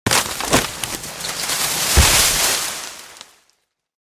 Free SFX sound effect: Tree Fall.
Tree Fall
Tree Fall.mp3